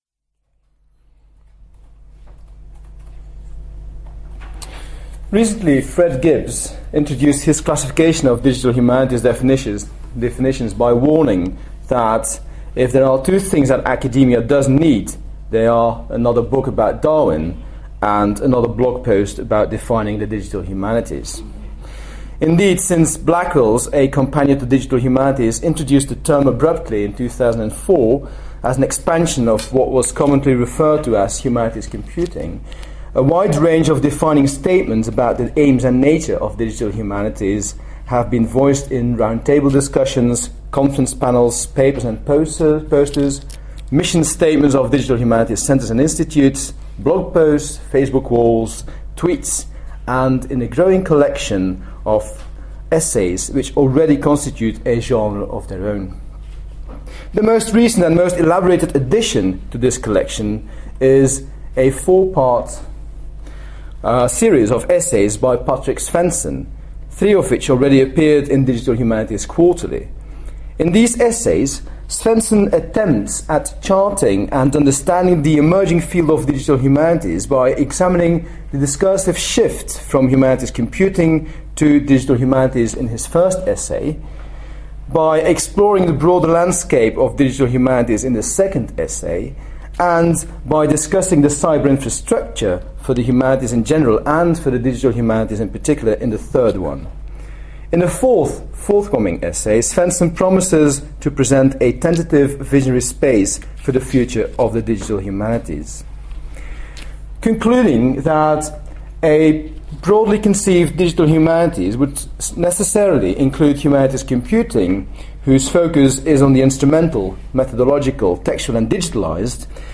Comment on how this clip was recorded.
at the Hidden Histories Symposium, September 2011, UCL